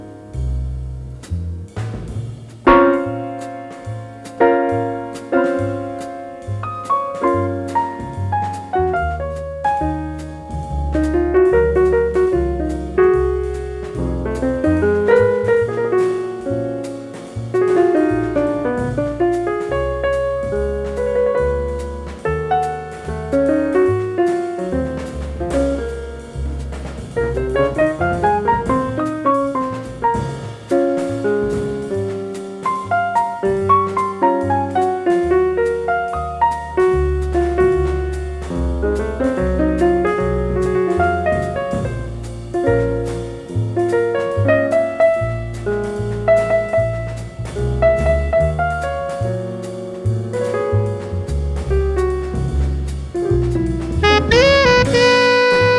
I made a mono version